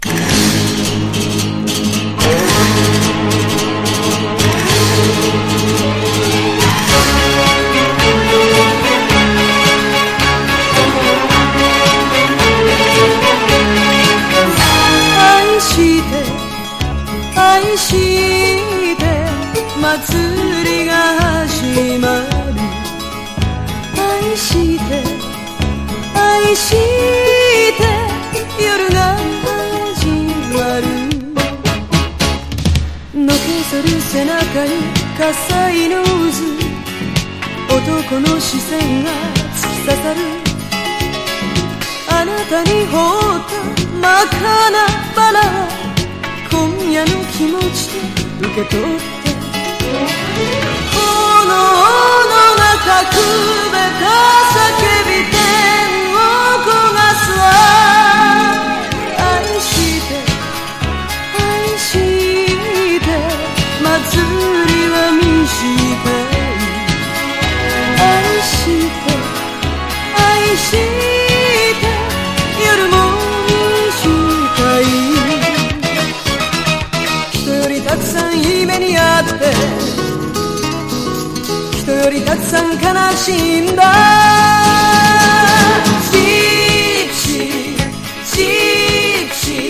POP
ポピュラー# 70-80’S アイドル